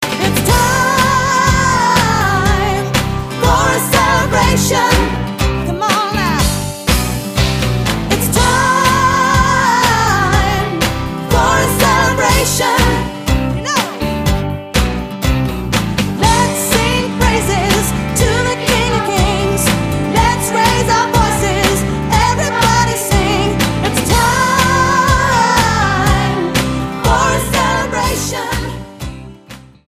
STYLE: Pop